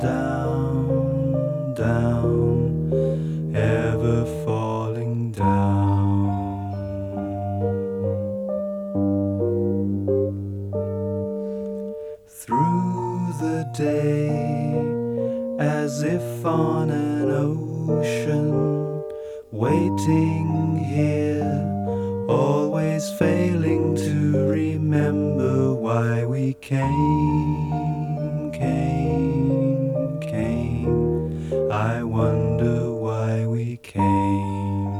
Жанр: Рок / Электроника